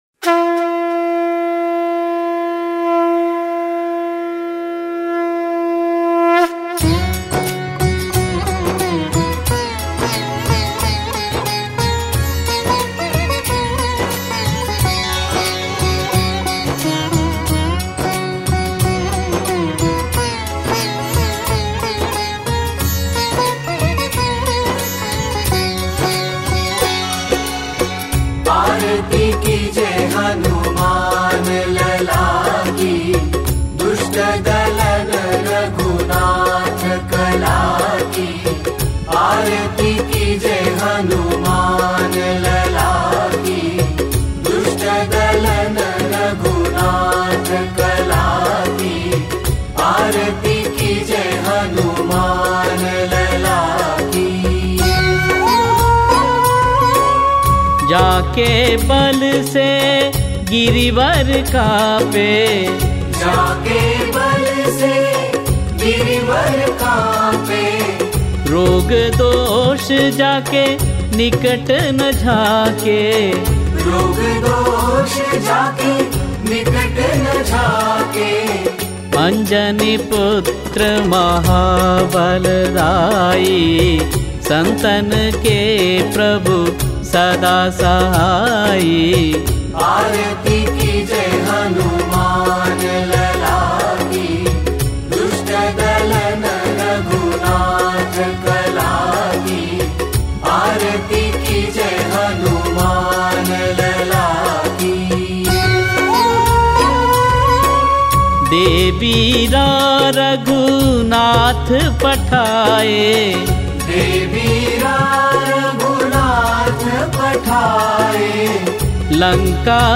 moderne uitvoering met klassieke Indiase instrumenten